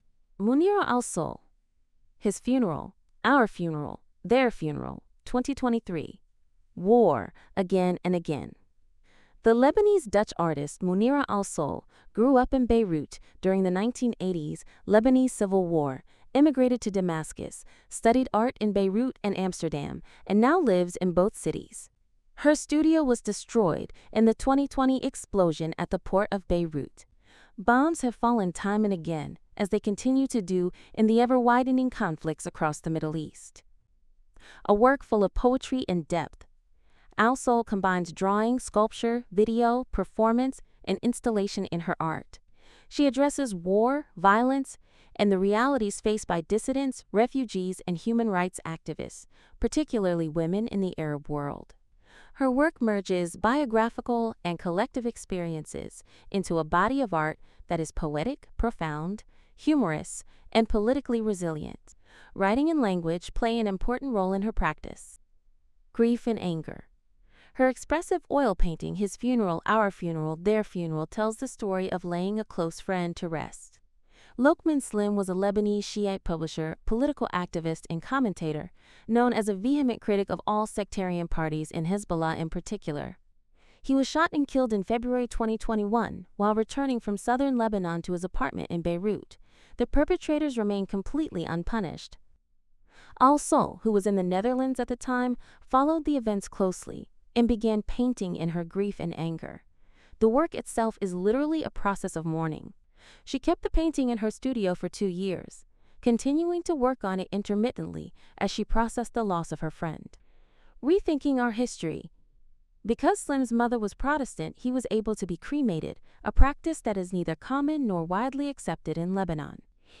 Note: The audio transcription is voiced by an AI.